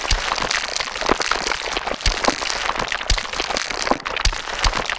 gore_short_loop.ogg